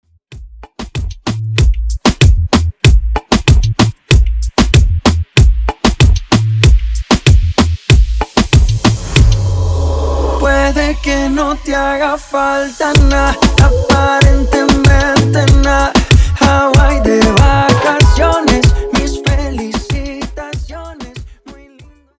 Extended Dirty Coro